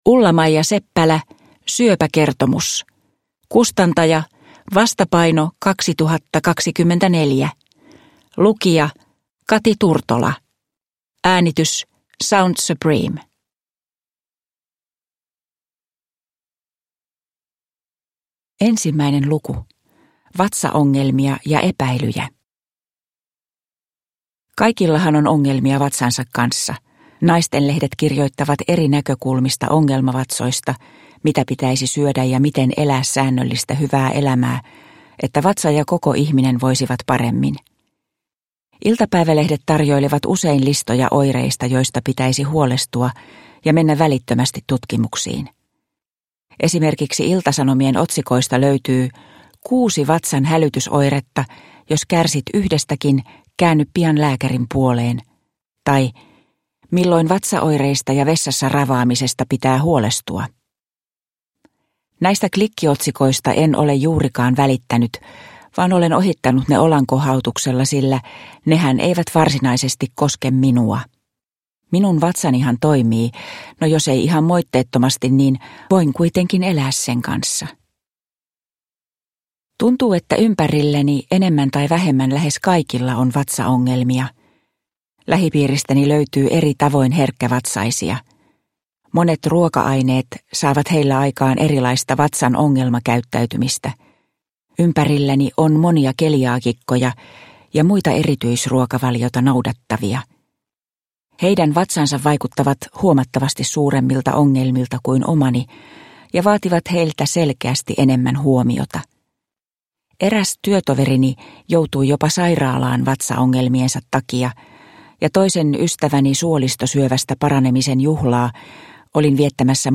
Syöpäkertomus – Ljudbok